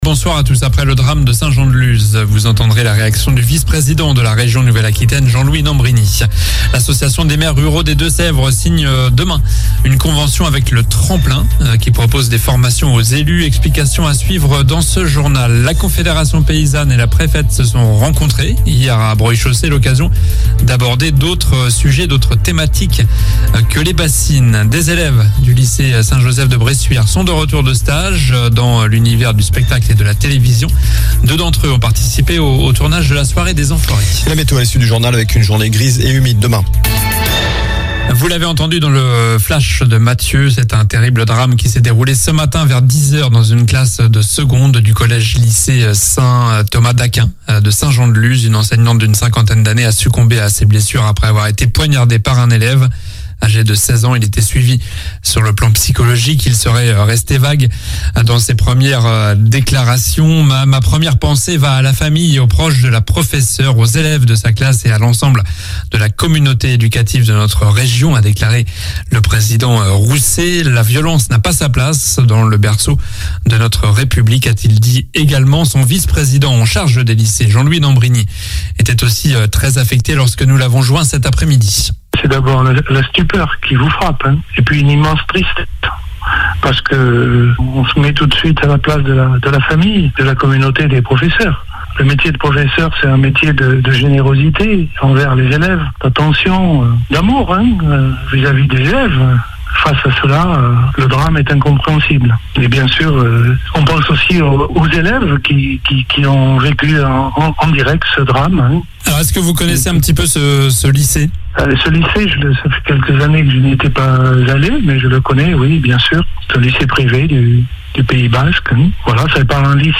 Journal du mercredi 22 février (midi)